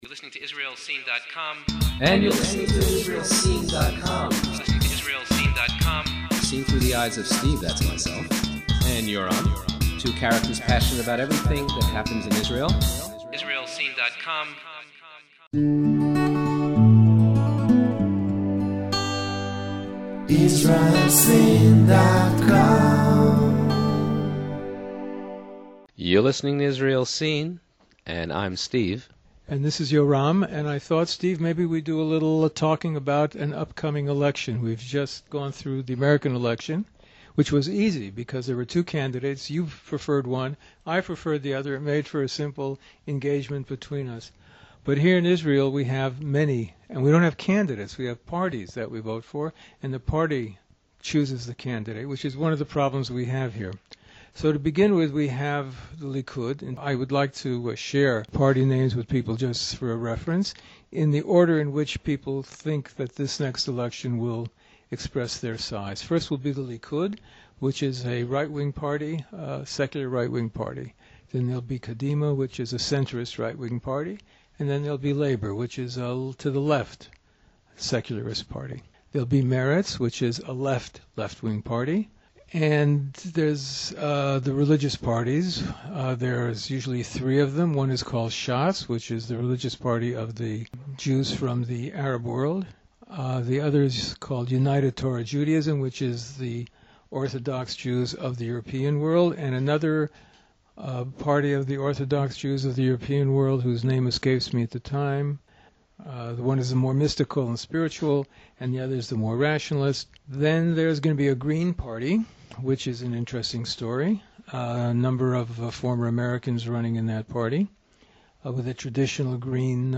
This will be the first of a few over the next couple of months as Israel gears up for new elections with the same old crew. We need a new political system if we are to have true Representative government. Listen in on the conversation.